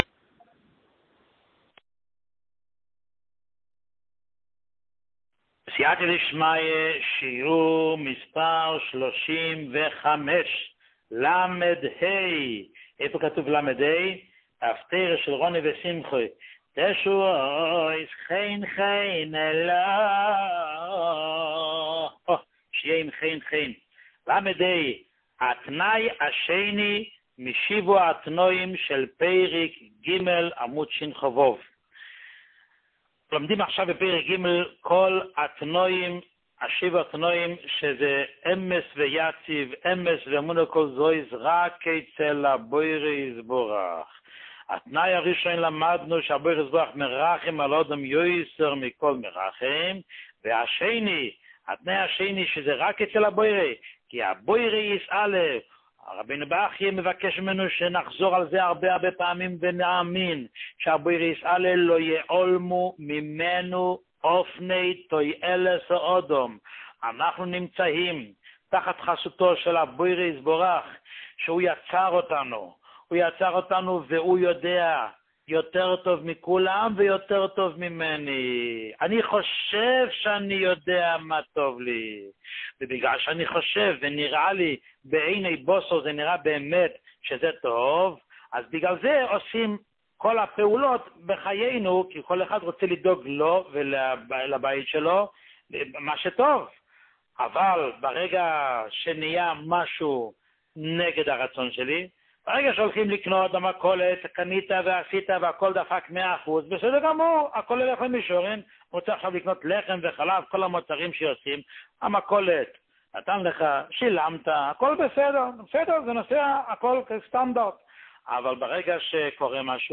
שיעור 35